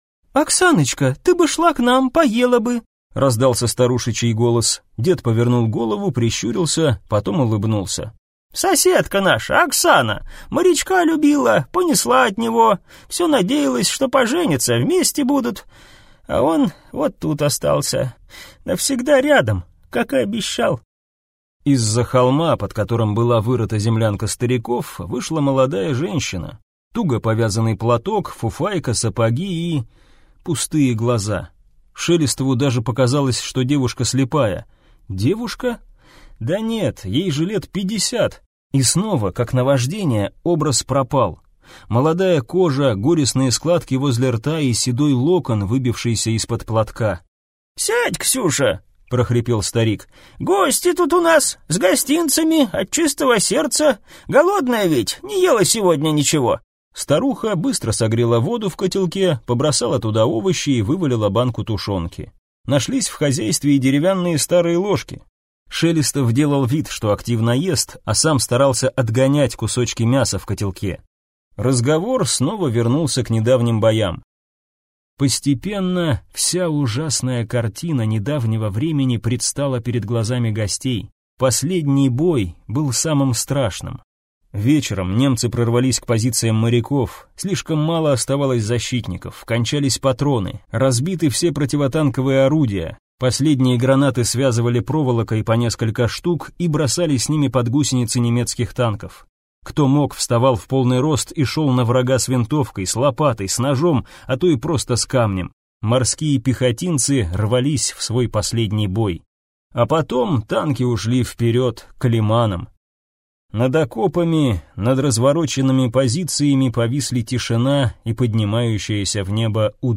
Аудиокнига Холодная акватория | Библиотека аудиокниг